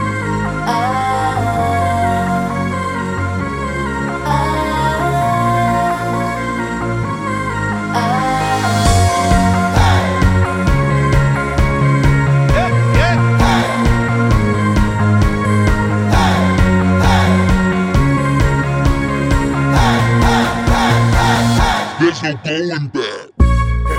Duet Version Pop (2000s) 3:33 Buy £1.50